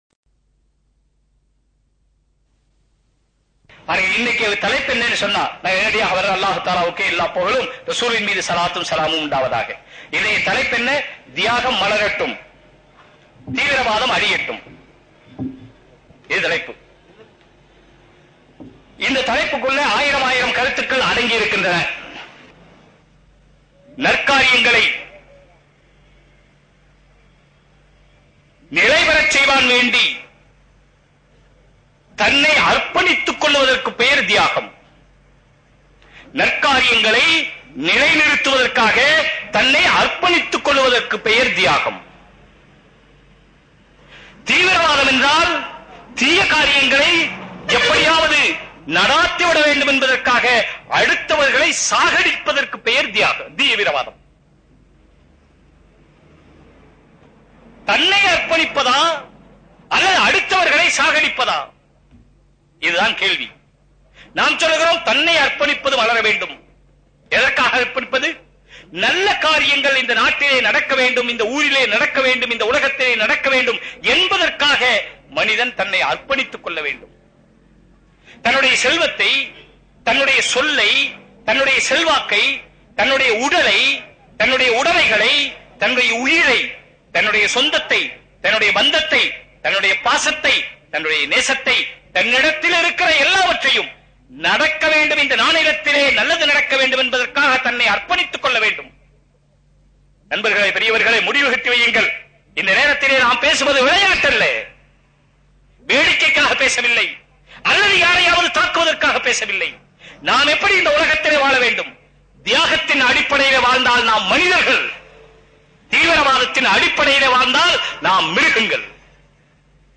ஒரு தெளிவான, அற்புதமான, ஆணித்தரமான உரை.